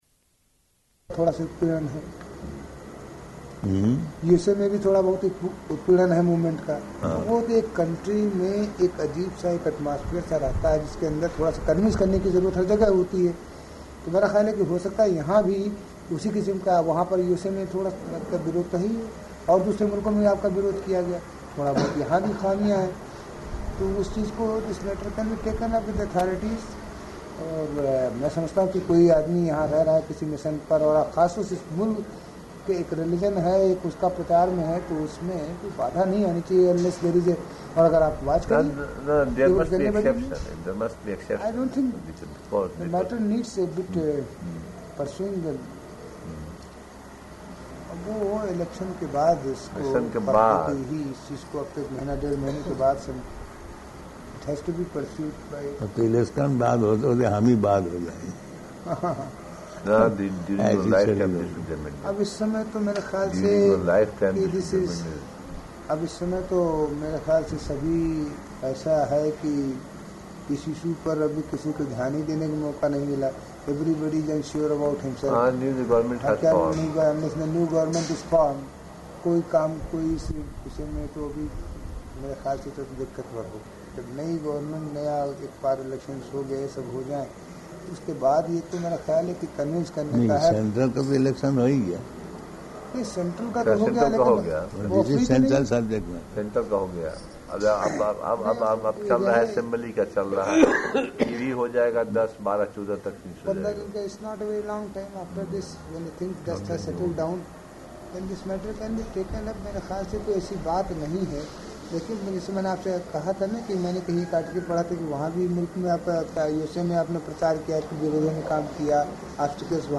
Room Conversation with Indian Guests
Room Conversation with Indian Guests --:-- --:-- Type: Conversation Dated: May 20th 1977 Location: Vṛndāvana Audio file: 770520R1.VRN.mp3 Indian man (1): [Hindi] Prabhupāda: Hmm?
[Hindi conversation with scattered English about getting permission for devotees to stay] Prabhupāda: [Hindi conversation] [long pause, bells ringing in background] [break] [end] Conversation with M.P., Shri Sita Ram Singh Room Conversation